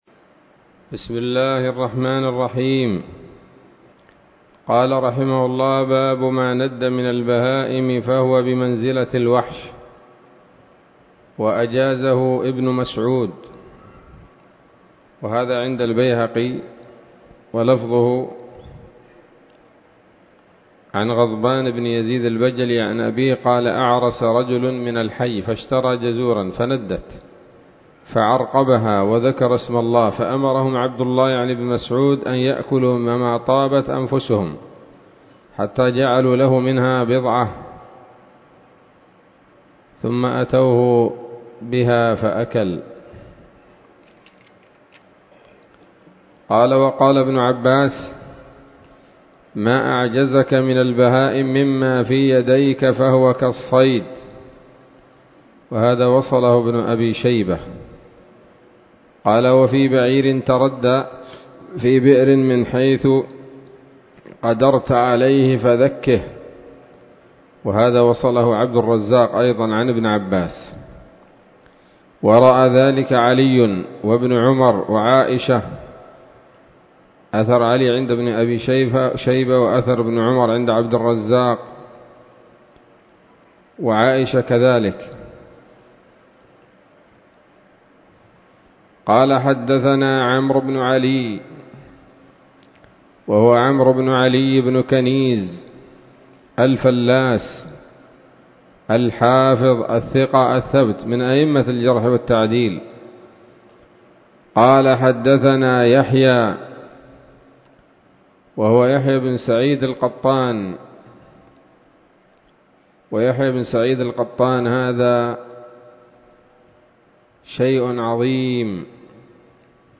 الدرس التاسع عشر من كتاب الذبائح والصيد من صحيح الإمام البخاري